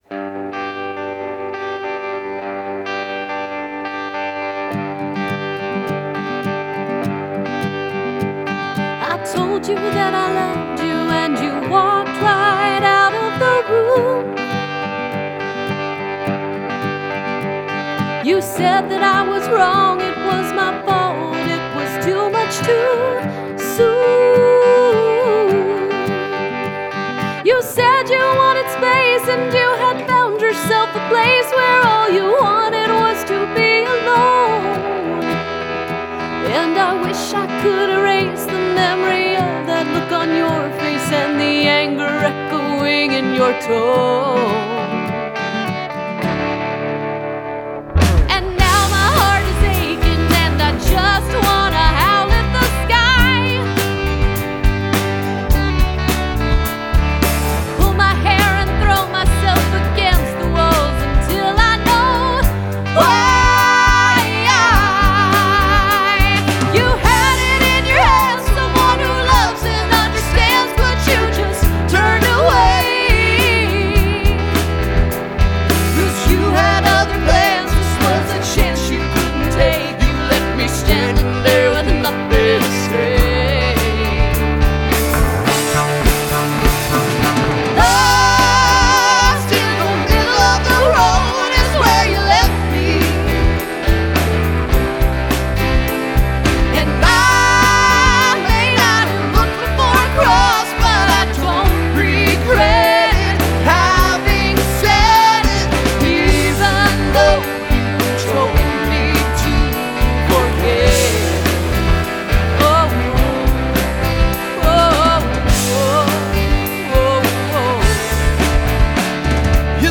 Жанр: Pop, Indie Pop, Country, Singer-Songwriter